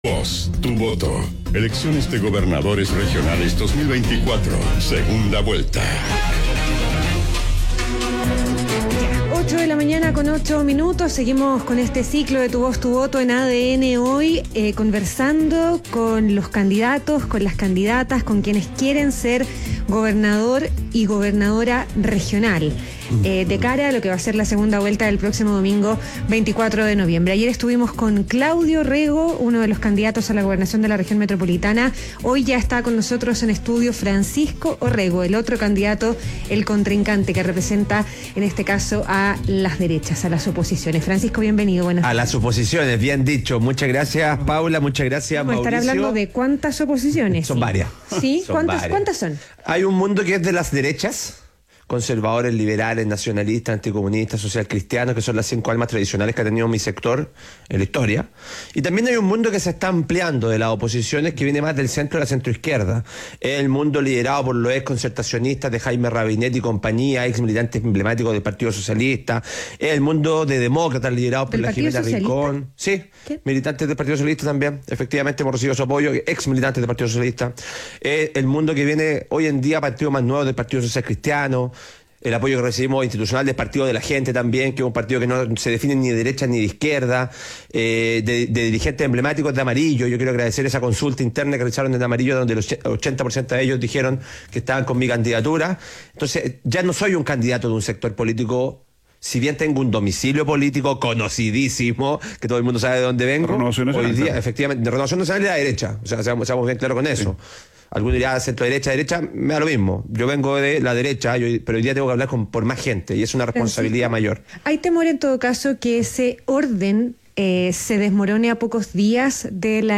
ADN Hoy - Entrevista a Francisco "Pancho" Orrego, candidato a gobernador RM